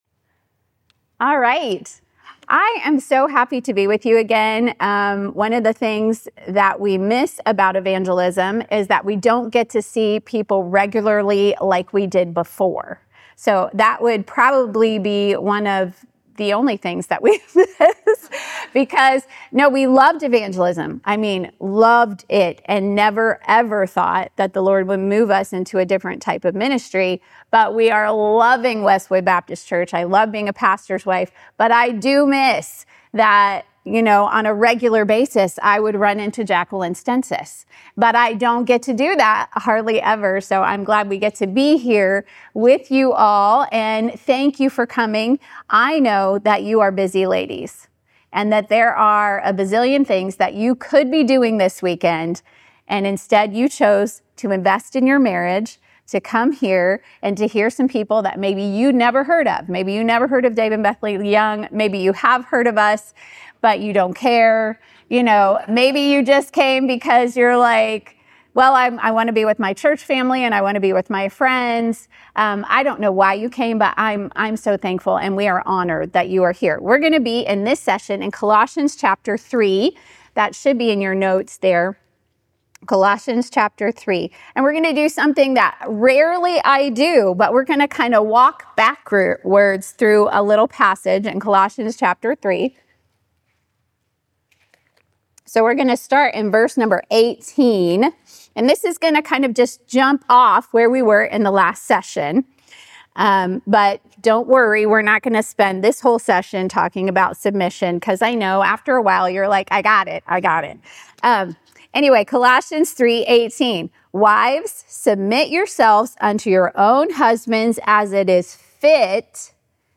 Sermons | First Baptist Church
Practical, encouraging, and full of real-life stories, this session helps wives make small daily decisions that lead to a fit, God-honoring marriage.